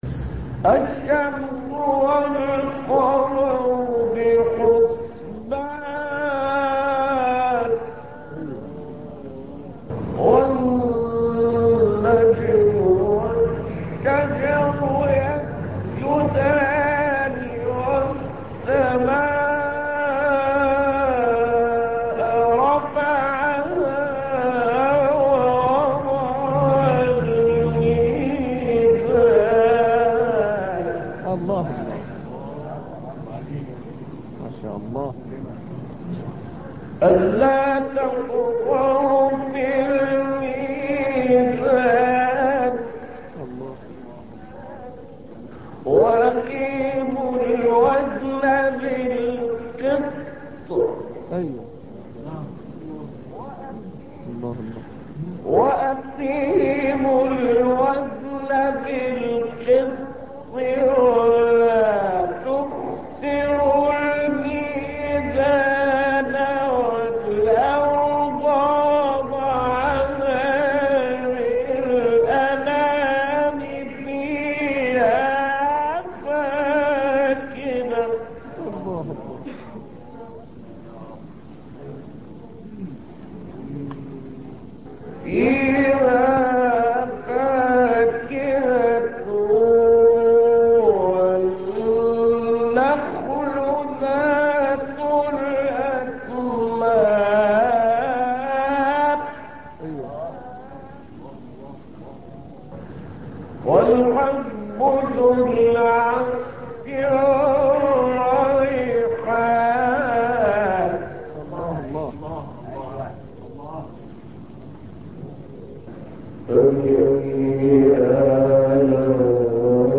نغمة النهاوند